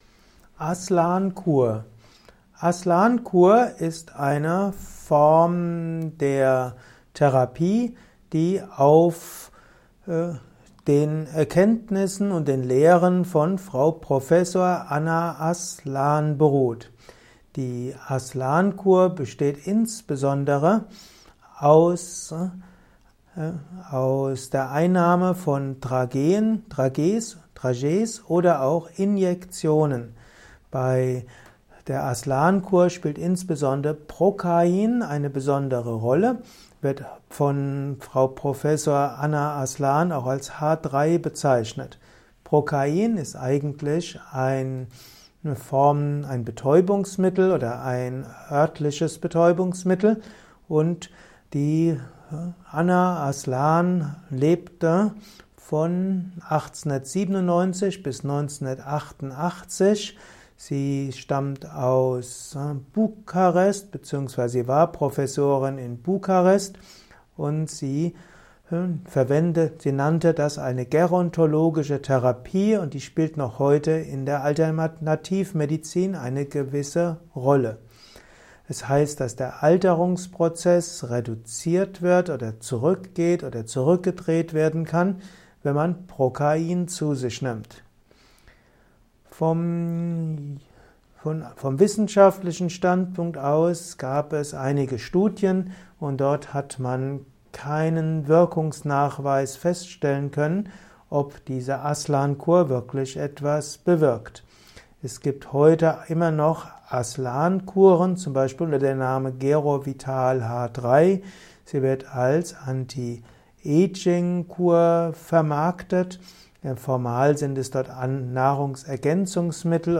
Audiovortrag zum Thema Aslan-Kur
Dieser Audiovortrag ist eine Ausgabe des Naturheilkunde Podcast.
Er ist ursprünglich aufgenommen als Diktat für einen